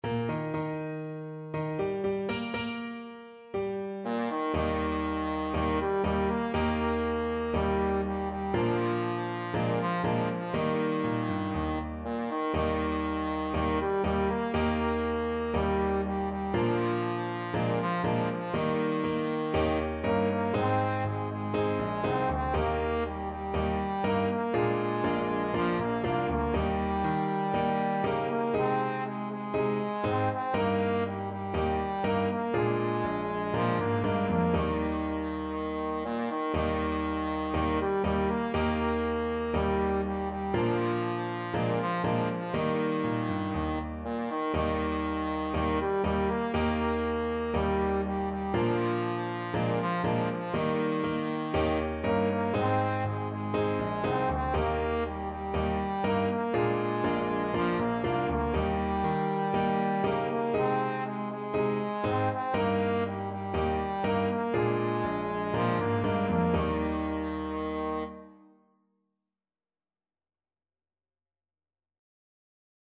4/4 (View more 4/4 Music)
Bb3-C5
March =c.120